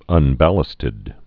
(ŭn-bălə-stĭd)